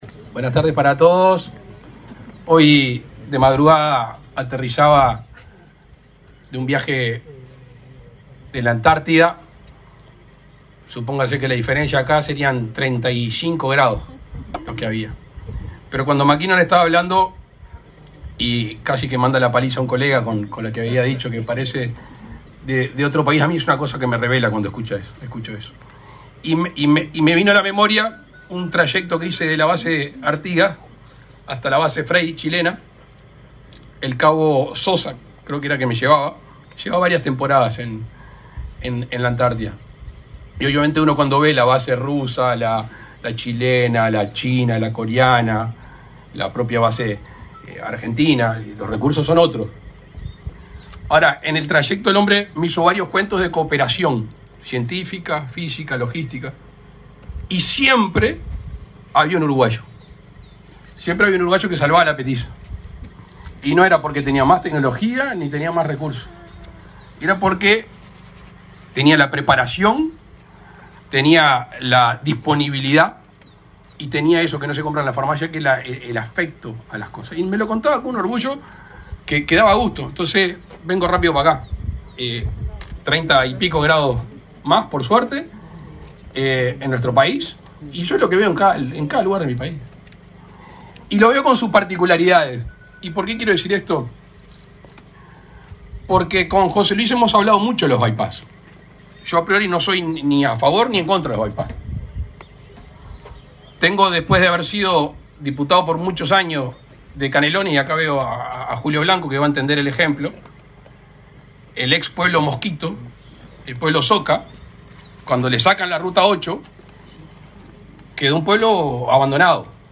Palabras del presidente de la República, Luis Lacalle Pou
Palabras del presidente de la República, Luis Lacalle Pou 21/12/2023 Compartir Facebook X Copiar enlace WhatsApp LinkedIn Con la presencia del presidente de la República, Luis Lacalle Pou, el Ministerio de Transporte y Obras Públicas inauguró, este 21 de diciembre, un bypass entre las rutas 3 y 11 y un nuevo puente sobre el río San José, en la ciudad homónima.